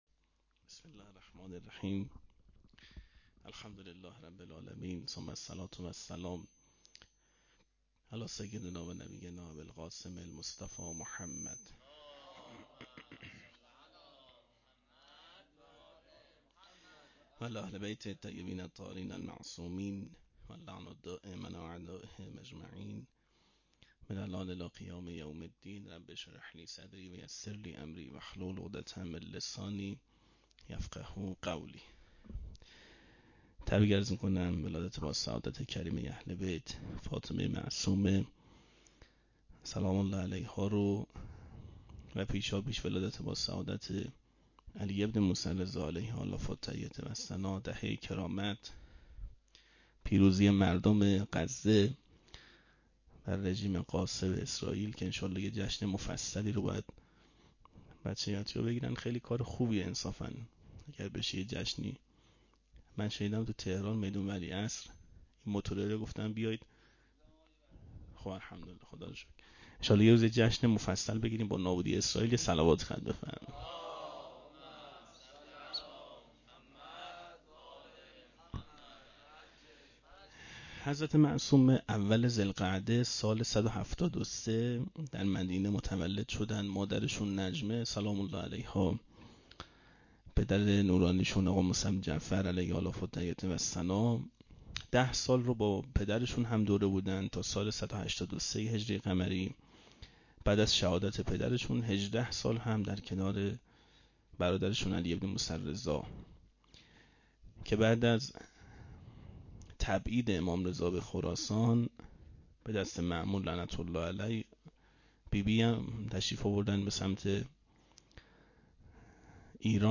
01-milad-hazrate-masome-sokhanrani.mp3